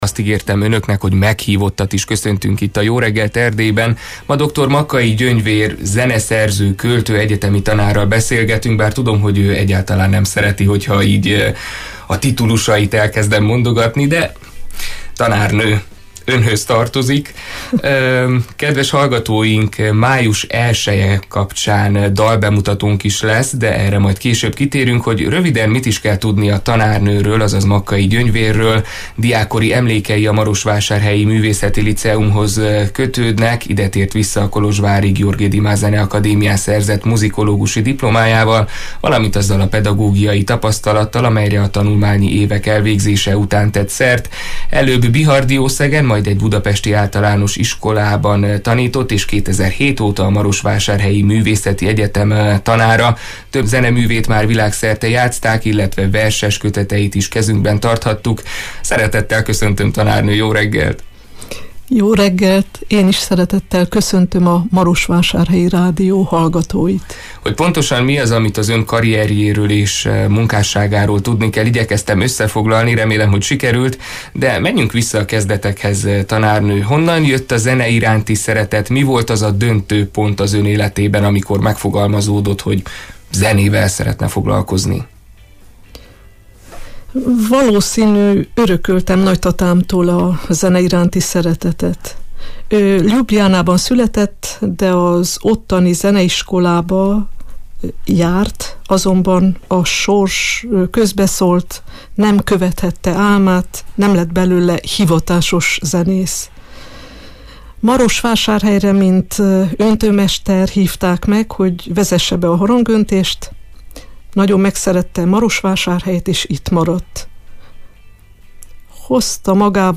Hogy mit jelenthet az ember életében a zene és a költészet, illetve mit kaphatunk a muzsikától, a mai beszélgetésből kiderül.